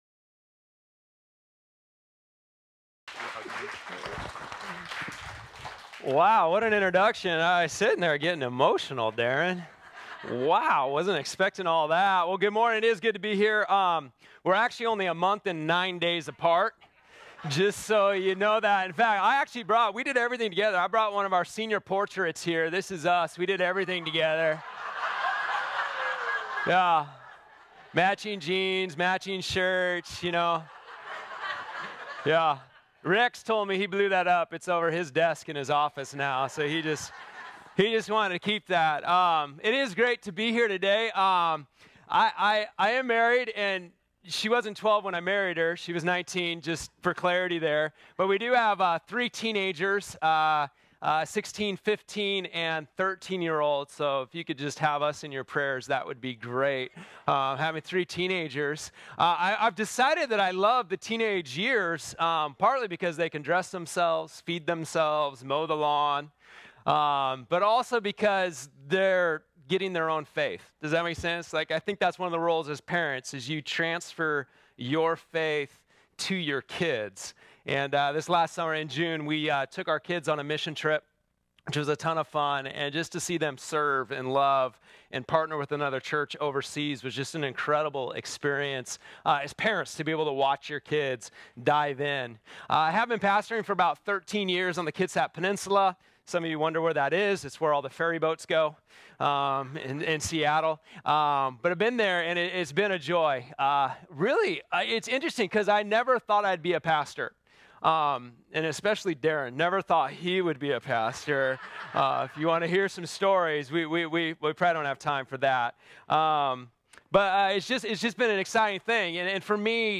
This sermon was originally preached on Sunday, July 28, 2019.